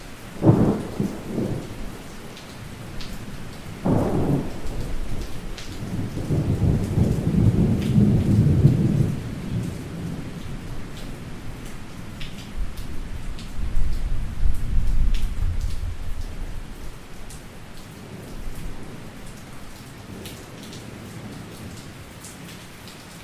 دانلود آهنگ رعد و برق 3 از افکت صوتی طبیعت و محیط
جلوه های صوتی
دانلود صدای رعد و برق 3 از ساعد نیوز با لینک مستقیم و کیفیت بالا